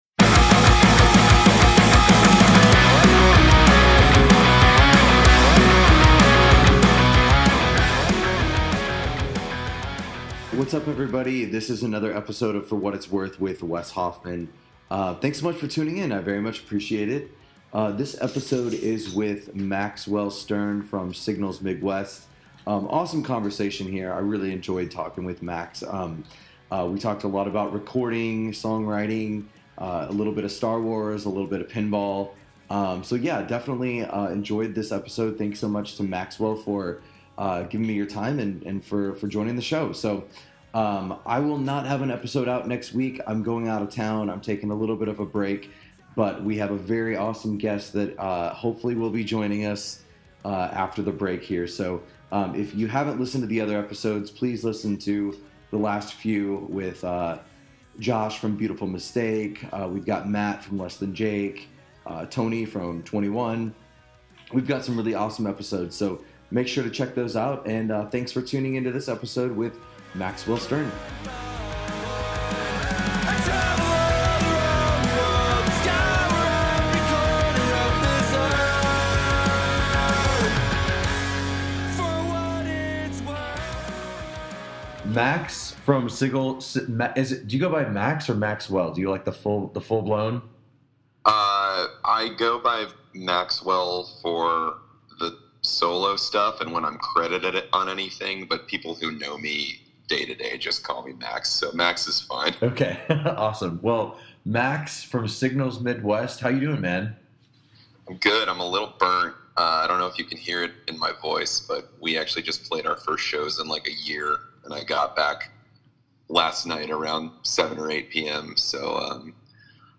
I apologize for the sound of my dog's collar shaking in the intro LOL!